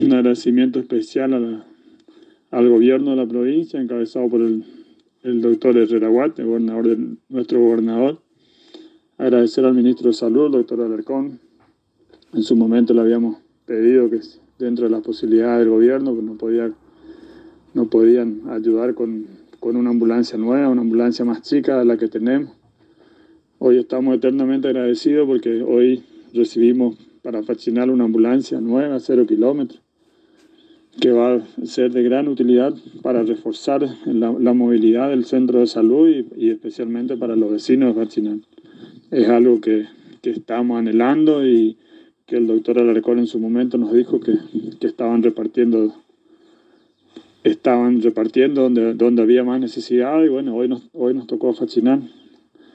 El Intendente de Fachinal Miguel Benítez en diálogo exclusivo con la ANG manifestó su agradecimiento al Gobernador Oscar Herrera y al Ministro de Salud Dr. Alarcón por la ambulancia que fue destinada al Municipio de Fachinal ya que permitirá agilizar los traslados al centro de salud.
Audio: Miguel Benítez, Intendente de Fachinal